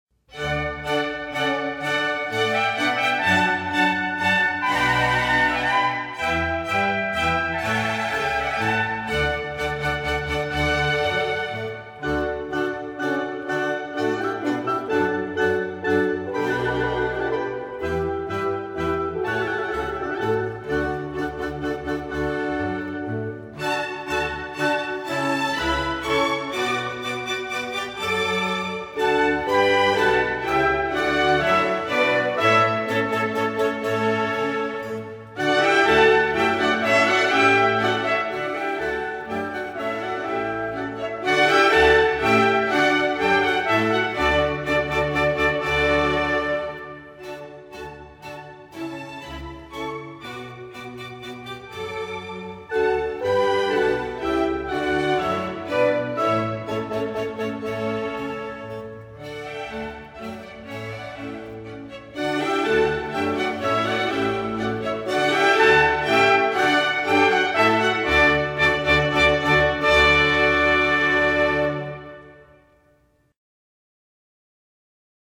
3. Minuet[1:14]
小步舞曲